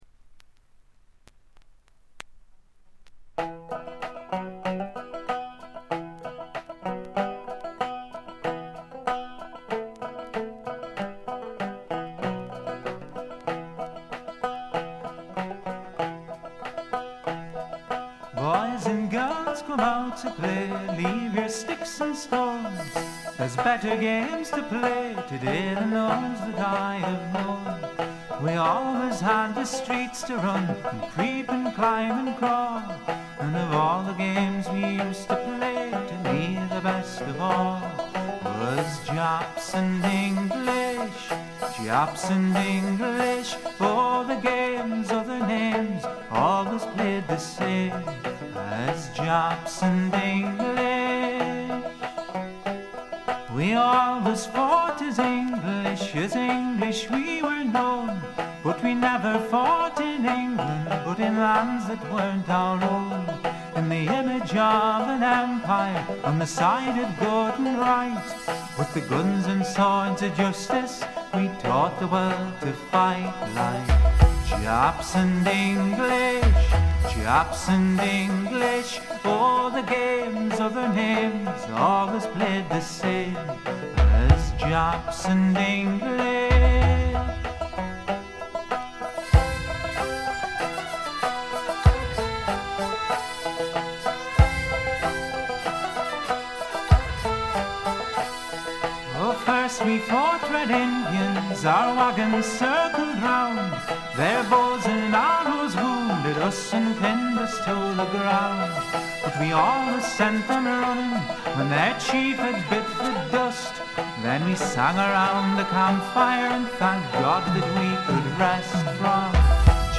ホーム > レコード：英国 フォーク / トラッド
試聴曲は現品からの取り込み音源です。
Electric Guitar, Drums [Linn], Backing Vocals